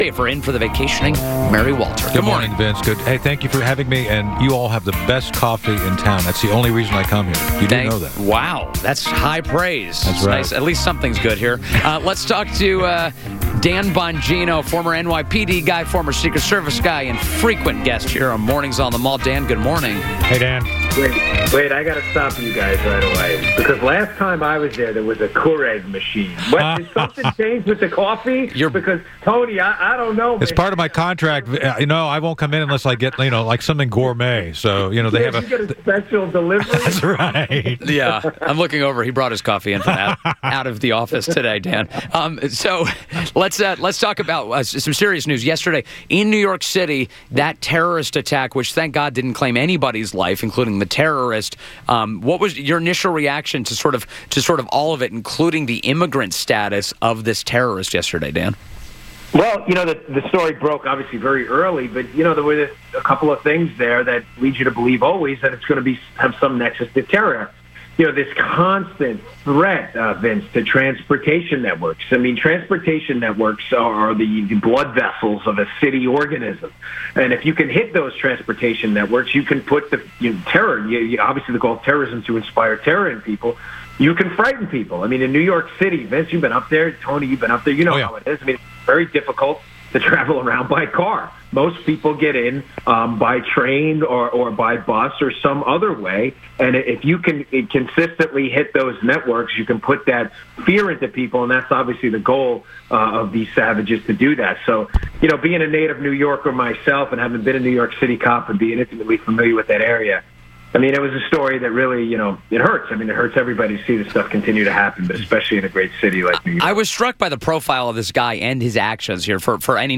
WMAL Interview - DAN BONGINO - 12.12.17
INTERVIEW - DAN BONGINO - former Secret Service agent and author of new book "Protecting the President" AND Bongino worked with the New York City Police Department for four years